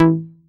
DOWN BASS E5.wav